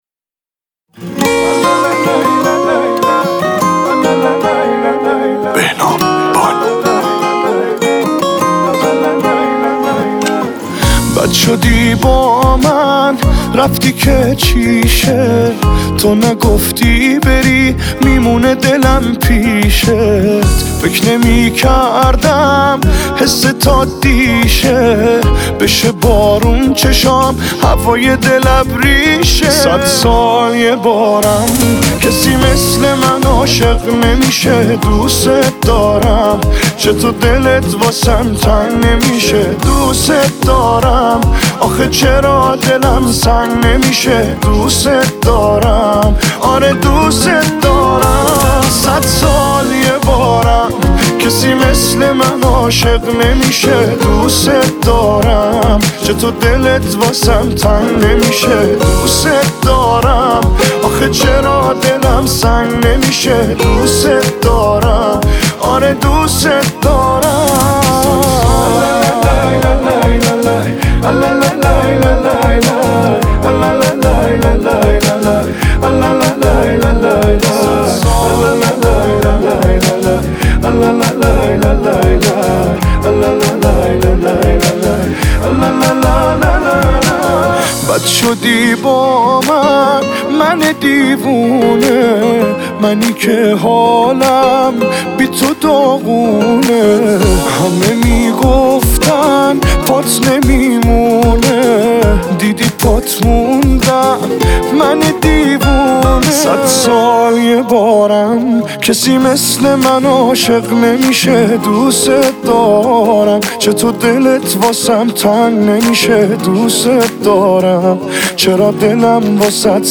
اهنگ احساسی و زیبای